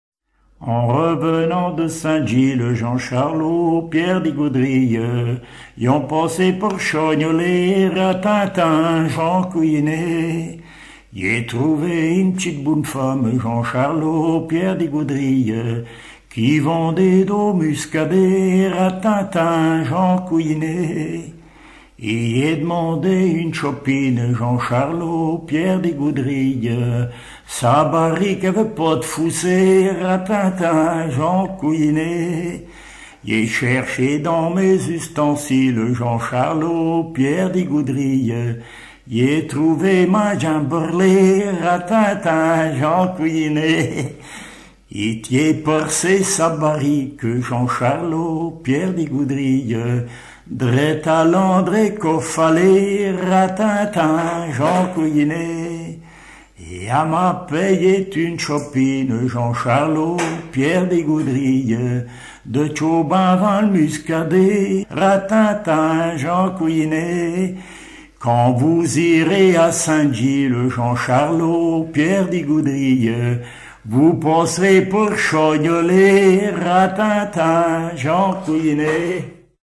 Localisation Saint-Michel-Mont-Mercure
Genre laisse